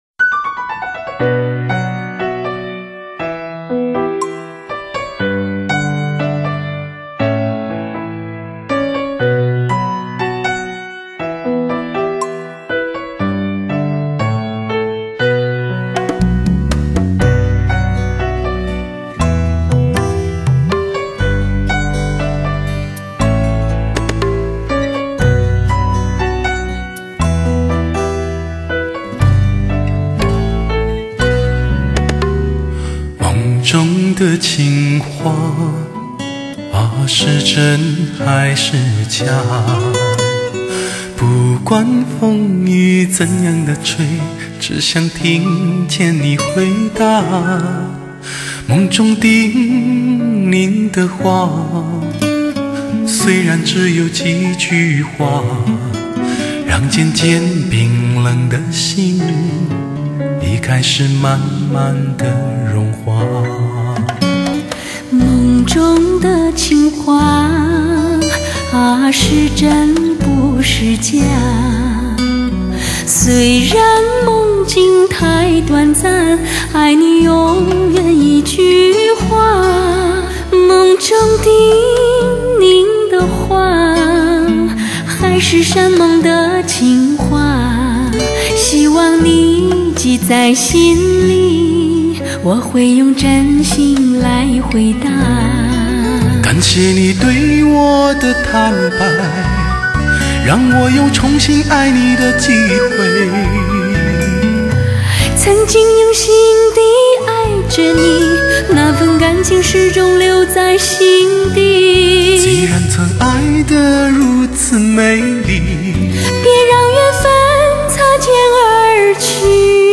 人声自然真情，乐器定位准确，玲珑浮凸，声音清晰通透，空间感活灵活现